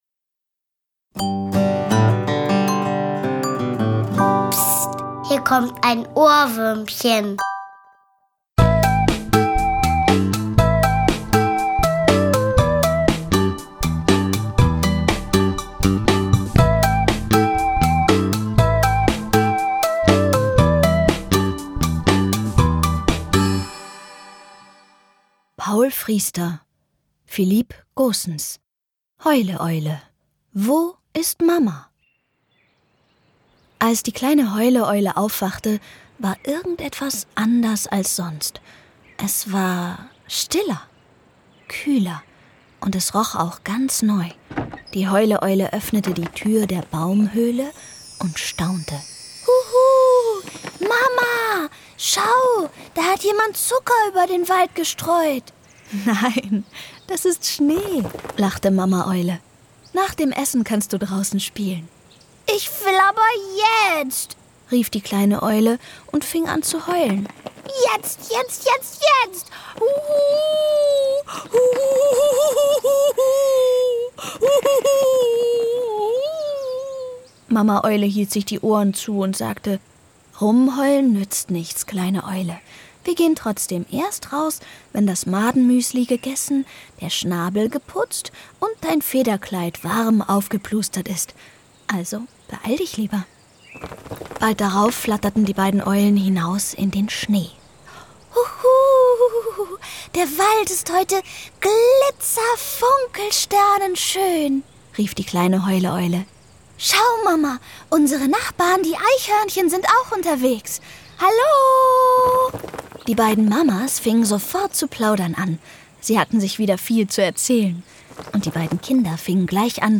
Untermalt mit vielen Geräuschen und Musik.